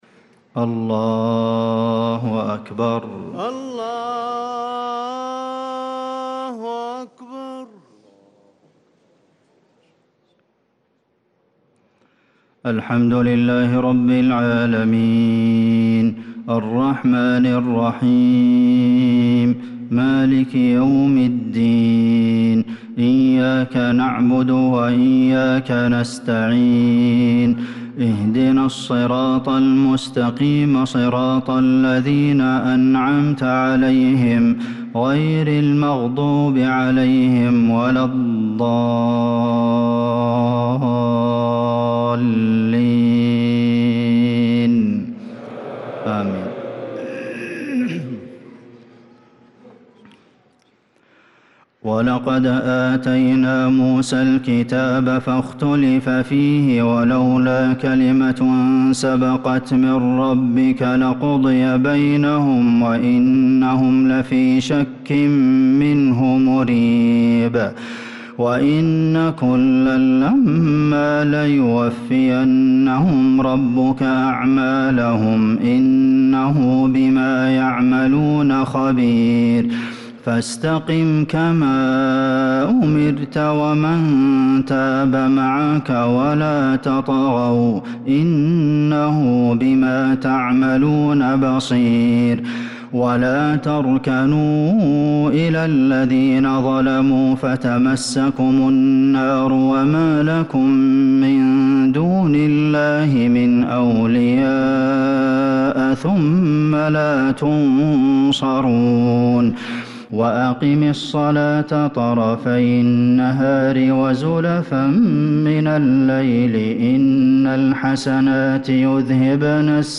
صلاة العشاء للقارئ عبدالمحسن القاسم 29 جمادي الأول 1446 هـ
تِلَاوَات الْحَرَمَيْن .